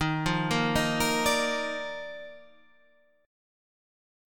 D#M7sus2 chord